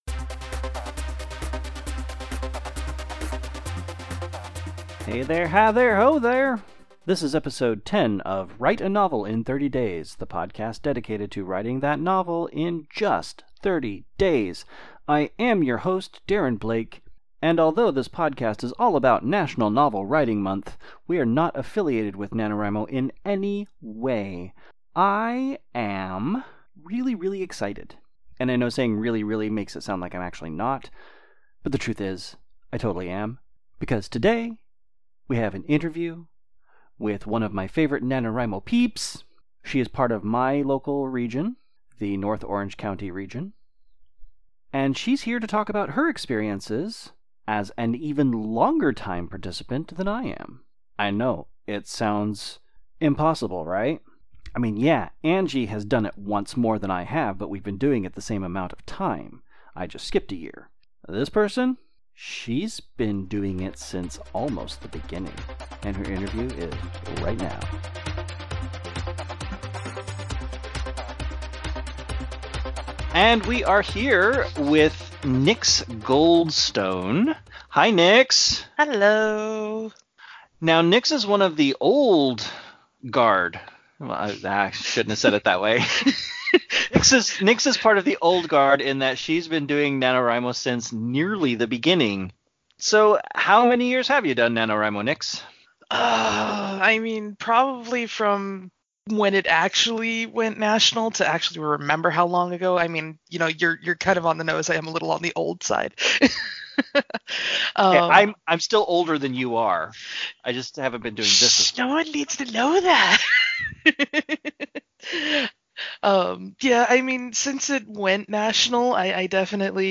As a reminder (forgot to mention it in the episode’s introduction), these episodes were all recorded prior to November, so you may hear some references to October events in the present tense.